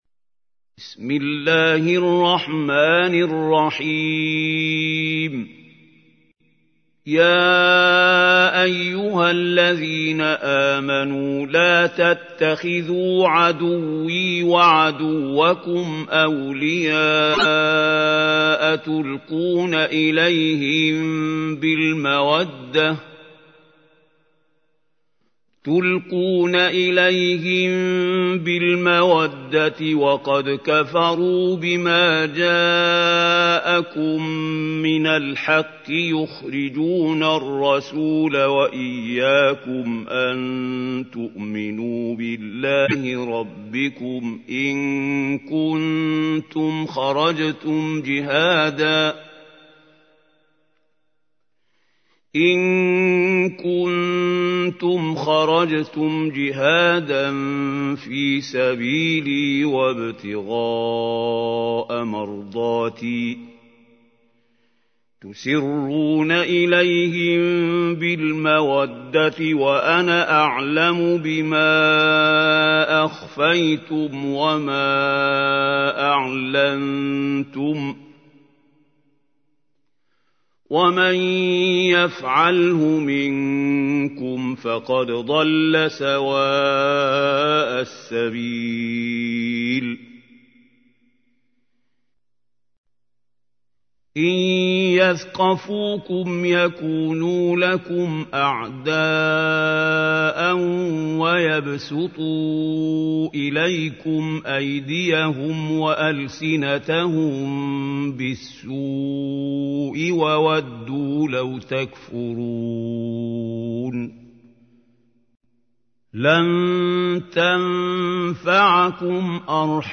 تحميل : 60. سورة الممتحنة / القارئ محمود خليل الحصري / القرآن الكريم / موقع يا حسين